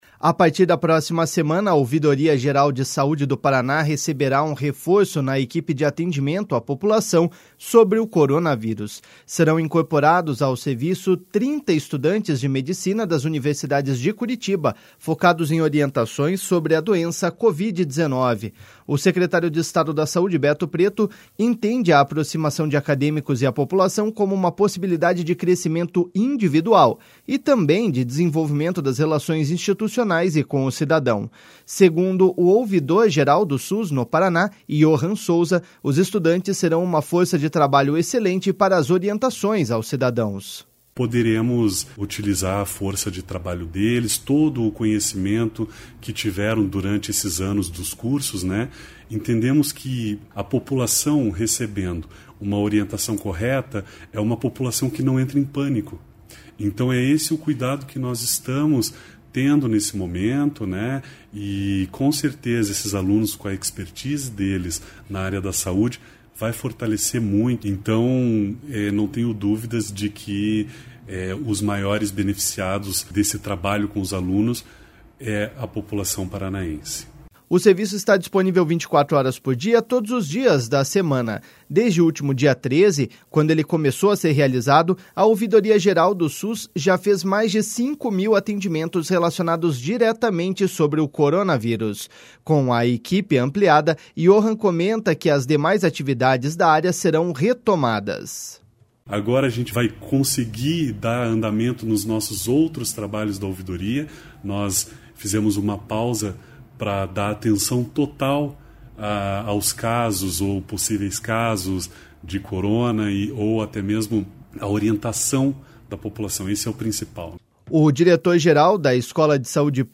Segundo o ouvidor-geral do Sus no Paraná, Yohhan Souza, os estudantes serão uma força de trabalho excelente para as orientações aos cidadãos.// SONORA YOHHAN SOUZA.//
O diretor-geral da Escola de Saúde Pública do Paraná/Centro Formador de Recursos Humanos, Edevar Daniel, explicou que foi publicado um edital de chamada pública para estudantes de medicina do 4º, 5º e 6º ano e a procura foi imediata.// SONORA EDEVAR DANIEL.//